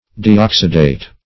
Deoxidate \De*ox"i*date\
deoxidate.mp3